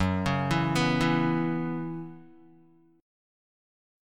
F#7sus4 chord